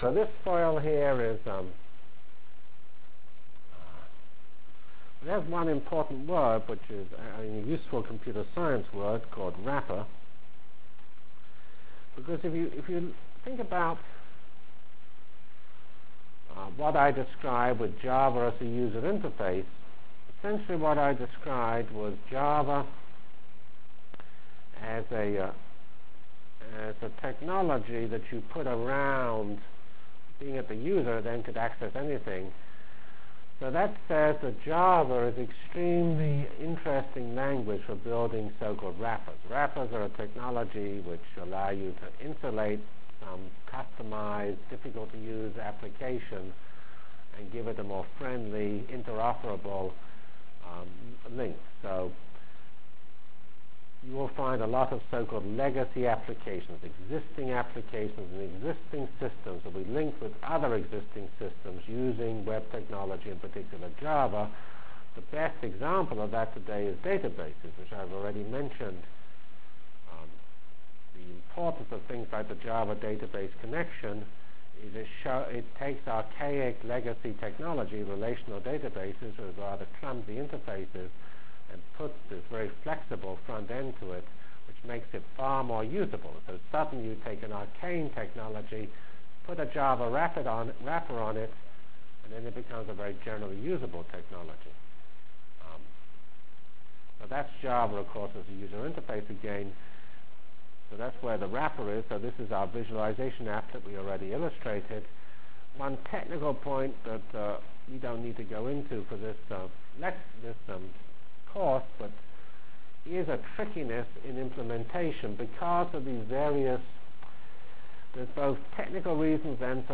From Feb 5 Delivered Lecture for Course CPS616 -- Java as a Computional Science and Engineering Programming Language CPS616 spring 1997 -- Feb 5 1997.